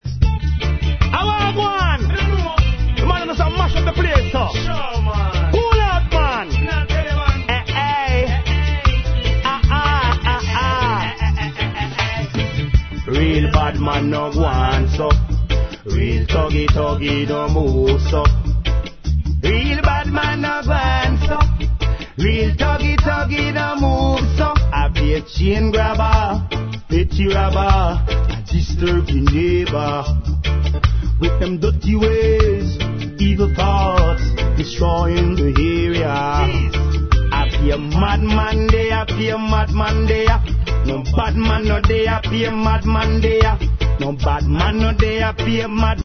Please post only reggae discussions here